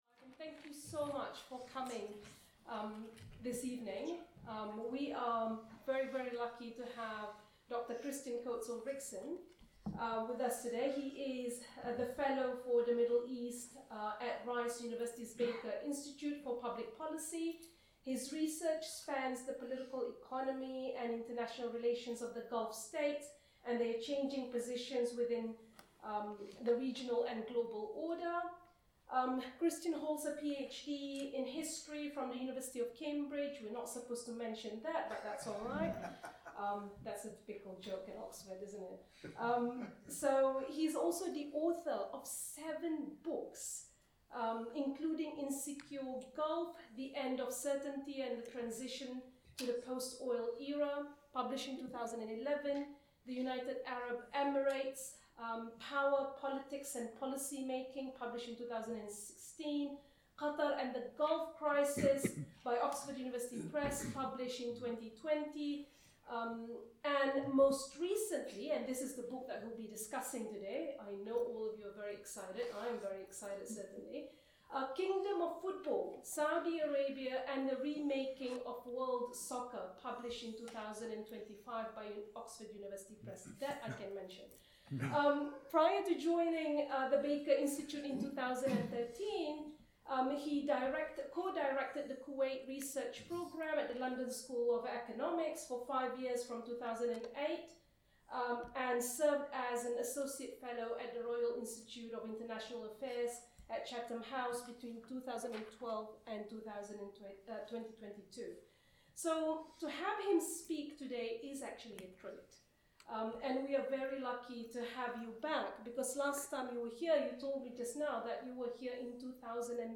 Middle East Centre Booktalk